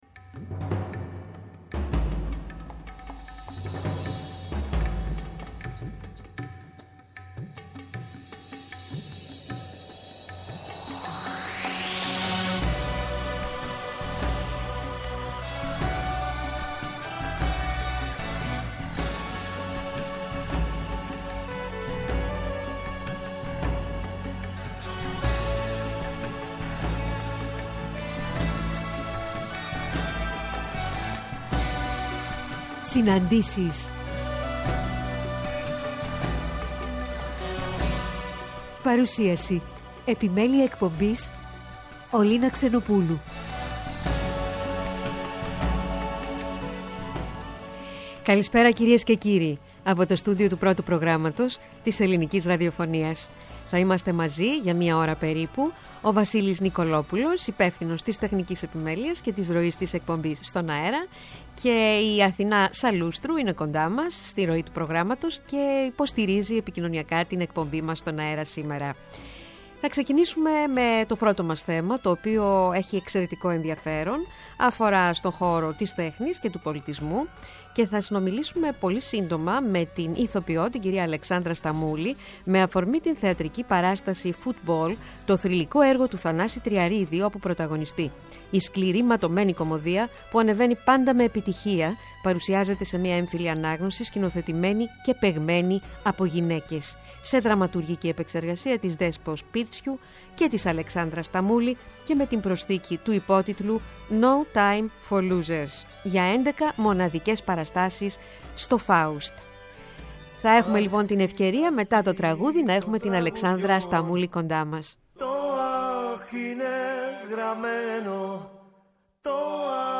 Στις ΣΥΝΑΝΤΗΣΕΙΣ στο ΠΡΩΤΟ ΠΡΟΓΡΑΜΜΑ την Κυριακή 29-01-23 ώρα 16:00-17:00 καλεσμένοι τηλεφωνικά: